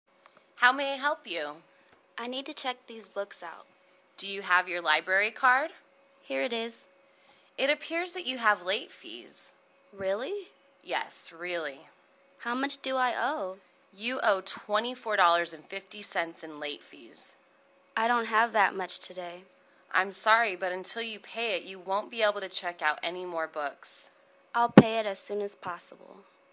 英语情景对话-Late Fees(1) 听力文件下载—在线英语听力室